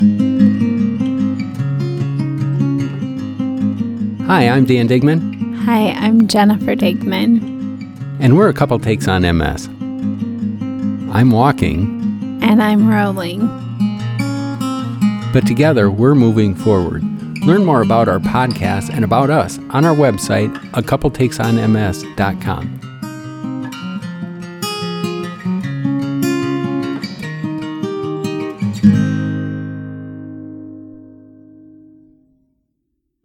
Podcast Promo 30 seconds